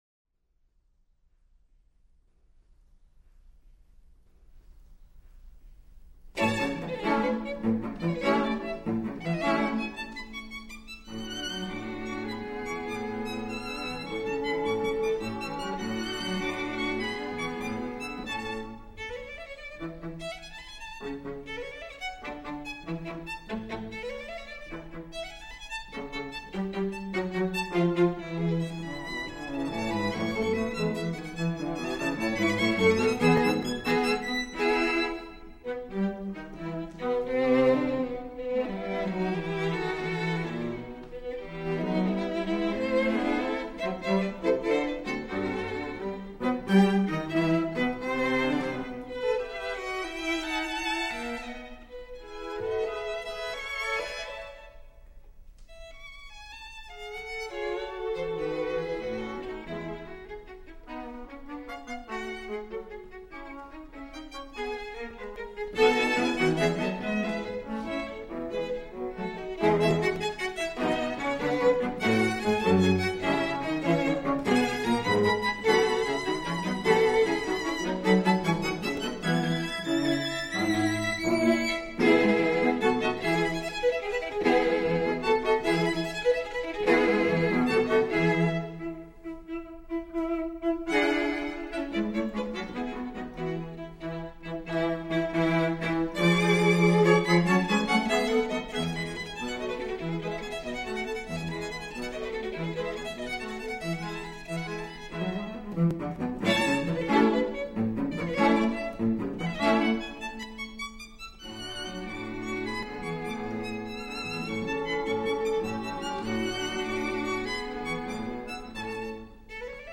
Performers: Guarneri Quartet (RCA 60456-2-RG)
As with the "Pathetique" Sonata, you can listen to this work for form (this particular quartet was chosen for the clarity and variety of its musical architecture) or for emotional content (generally much cheerier than the "Pathetique" Sonata, though there are a few stormy parts).
different sizes of instruments, and they occupy about the same ranges as the four parts of a choir:
Violin II                        (alto)
Viola                           (tenor)
Cello                            (bass)
The first movement uses sonata-allegro form, like the first movement of the "Pathetique" Sonata except there is no slow introduction.
The second theme should be easy to recognize since it begins in a minor key with a unison passage, which makes it sound rather threatening or foreboding.